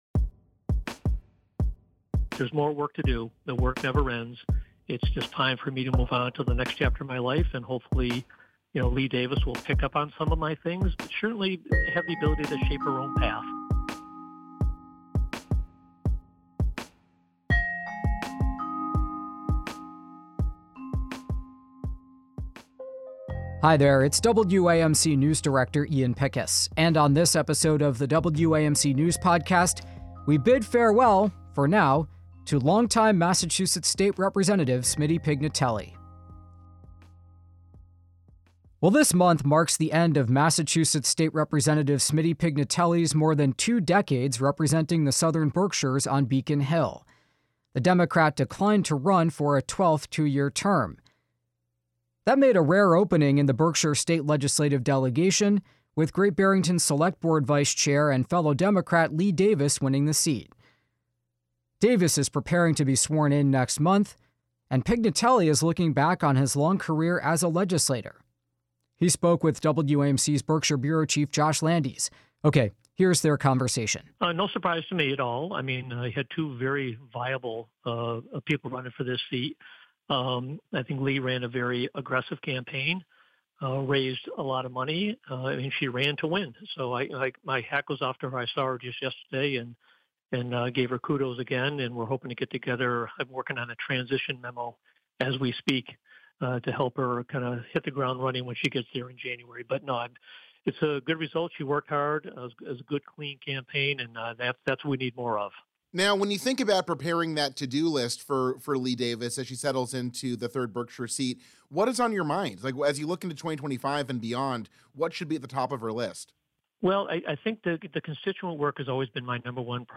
We speak with outgoing Mass. state Rep. Smitty Pignatelli, who is leaving the 3rd Berkshire House district after 12 terms.